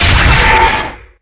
pain1.wav